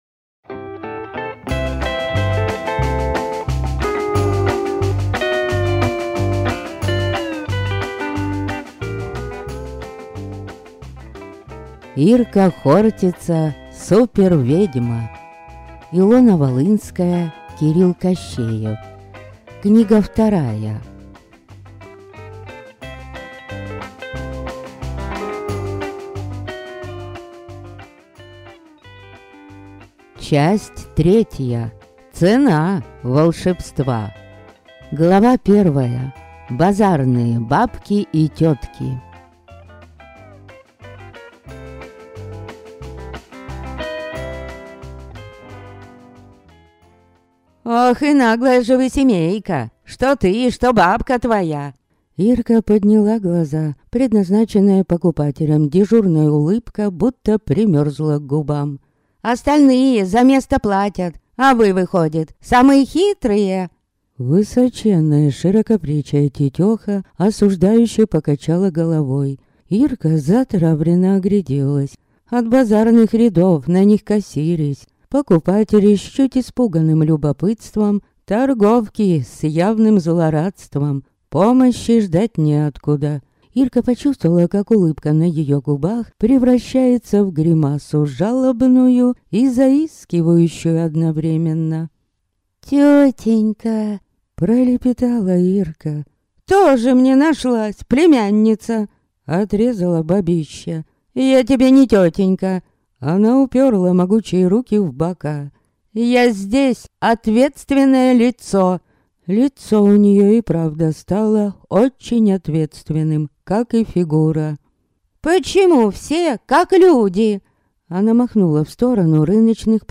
Аудиокнига Фан-клуб колдовства (части 3 и 4) | Библиотека аудиокниг